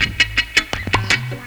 RIFFGTRLP1-L.wav